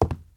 PixelPerfectionCE/assets/minecraft/sounds/step/wood1.ogg at mc116
wood1.ogg